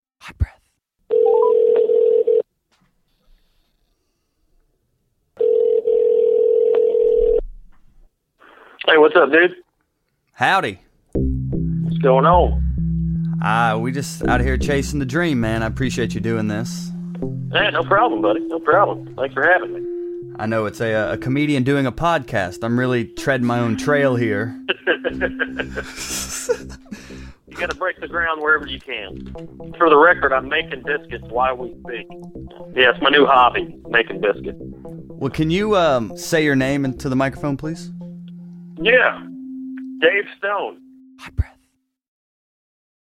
This interview traces every step and ounce of wisdom gained along the way.